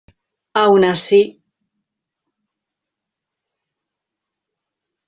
Pronunciation Es Aun Así (audio/mpeg)